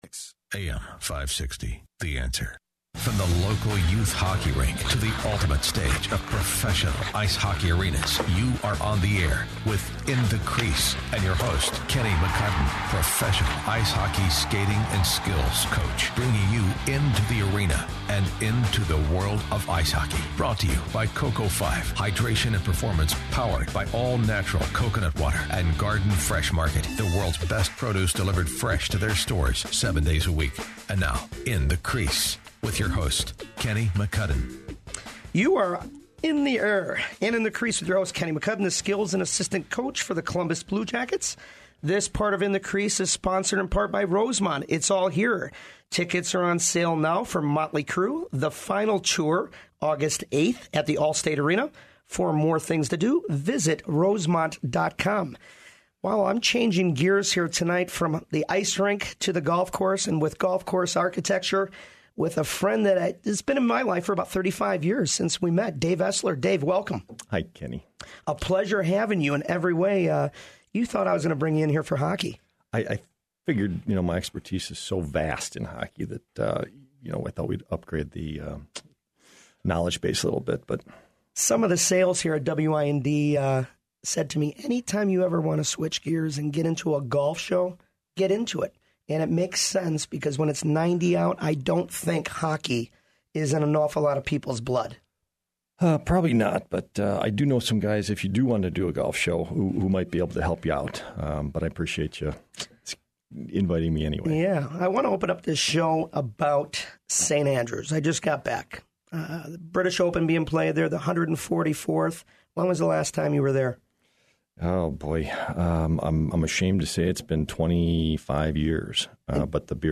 Radio Interview AM560 The Answer